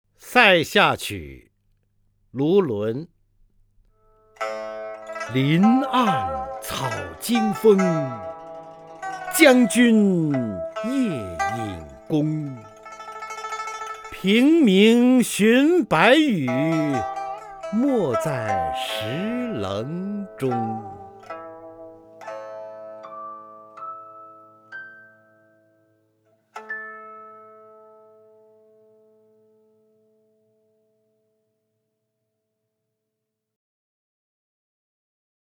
方明朗诵：《塞下曲六首·其二》(（唐）卢纶) （唐）卢纶 名家朗诵欣赏方明 语文PLUS